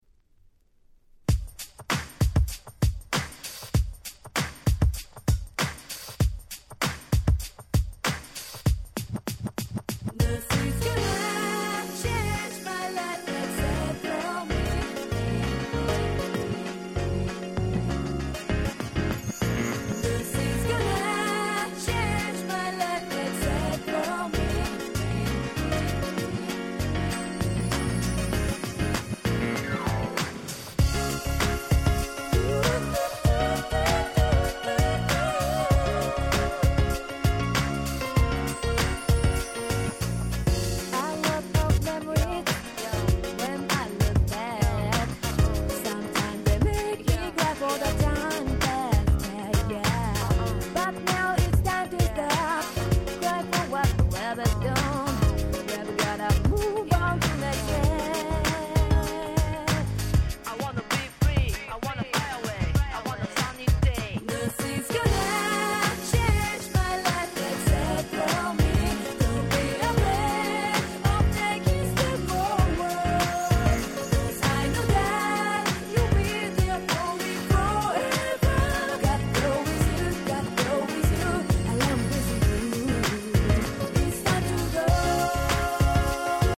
08' Nice Japanese R&B !!